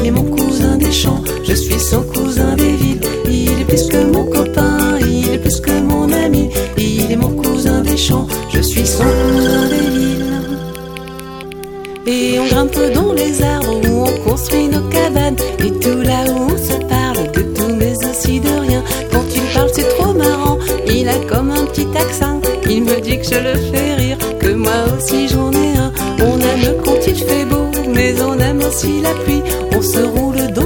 "enPreferredTerm" => "Chansons pour enfants"